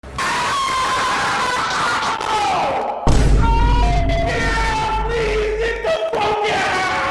Funny Loud Scream 1